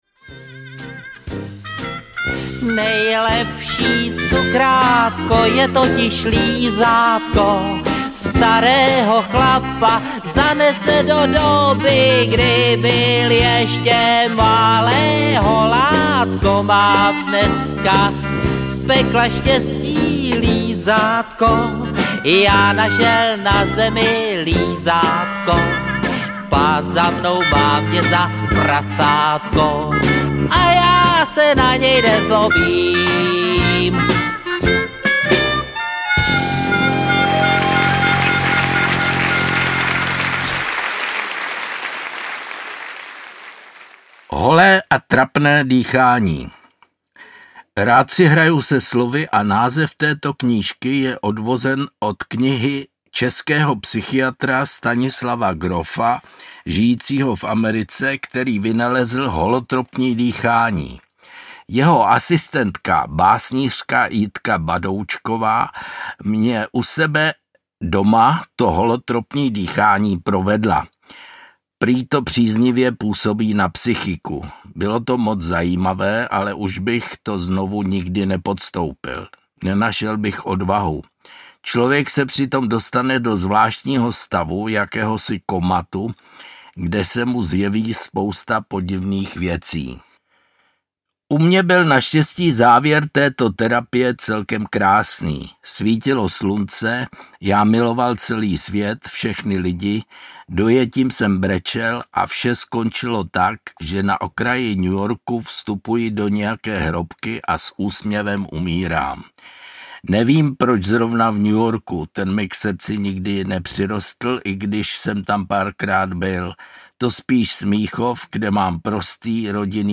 Holé a trapné dýchání audiokniha
Ukázka z knihy
Píše celý život kratší literární útvary, které vyšly knižně a nyní je pod stejným názvem autor načetl (v tomto kompletu jich najdete celkem 43).